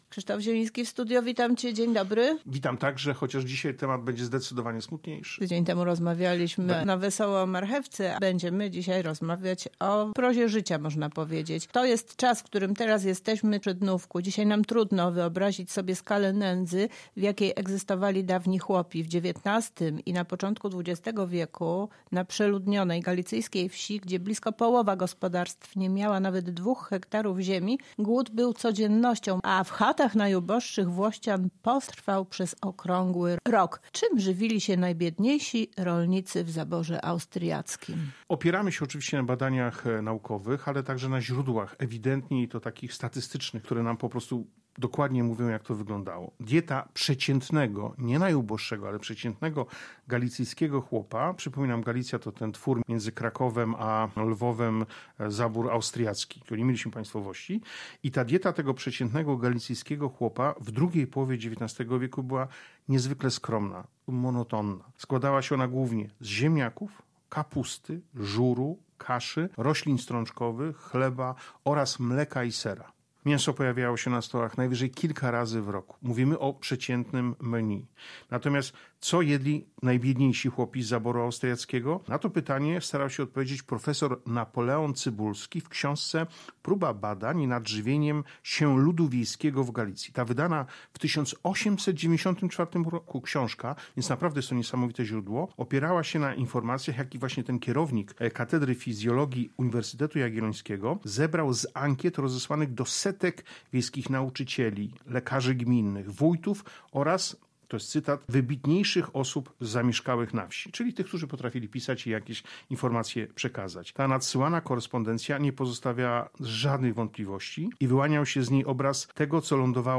historyk i regionalista, znawca jedzenia i tradycji kulinarnych.